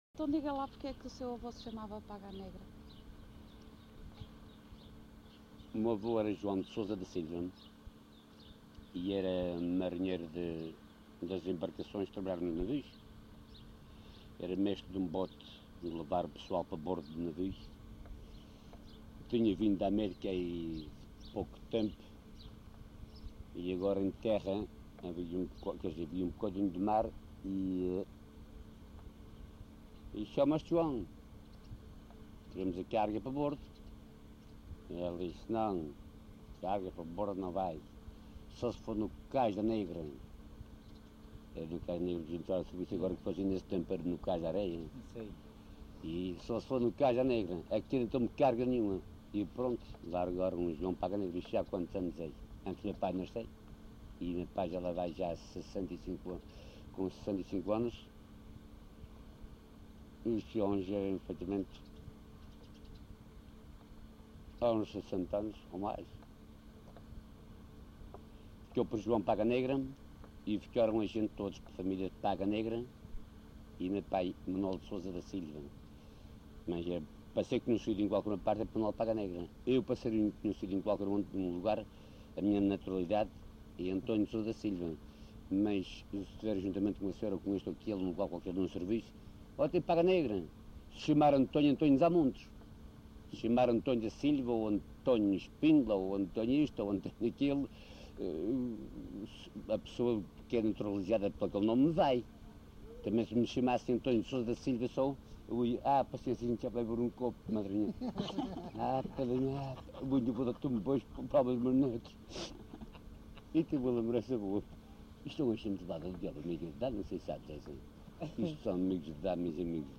LocalidadeCarapacho (Santa Cruz da Graciosa, Angra do Heroísmo)